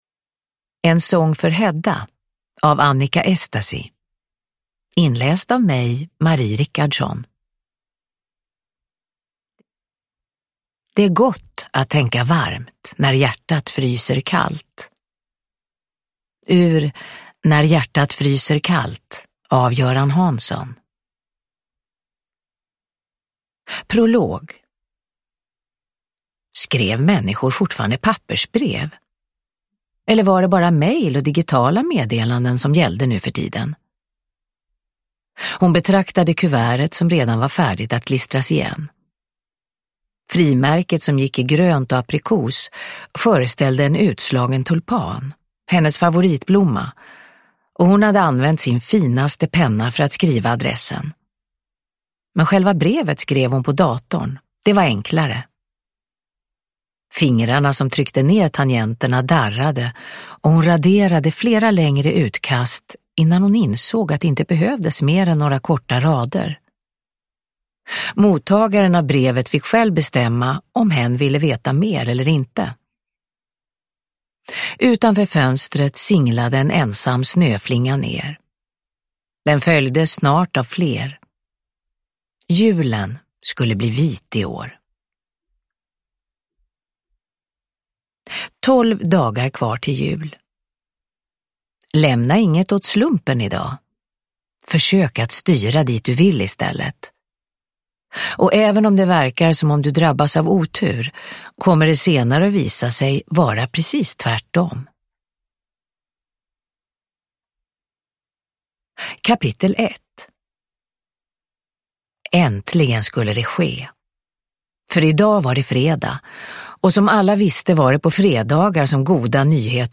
En sång för Hedda – Ljudbok – Laddas ner
Uppläsare: Marie Richardson